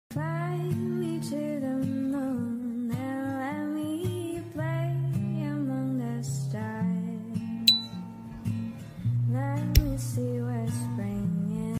Test âm thanh Dupont phay sound effects free download
Test âm thanh Dupont phay xước vàng được làm từ thép nguyên khối